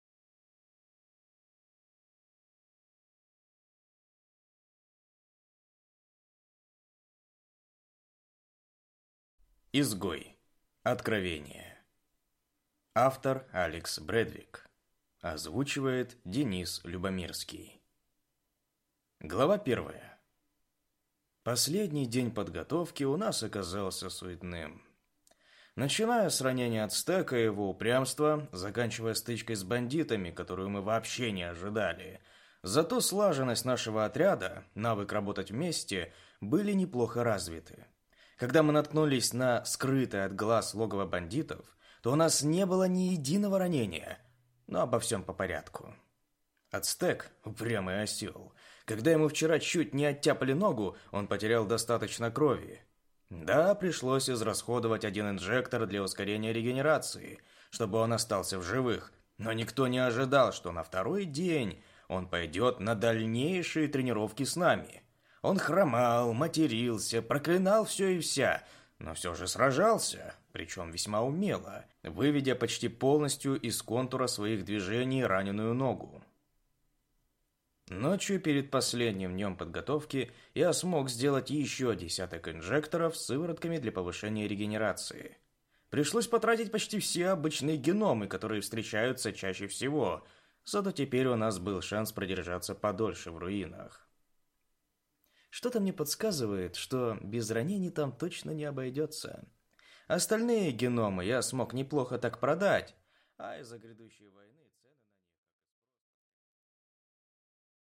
Аудиокнига Изгой. Откровение | Библиотека аудиокниг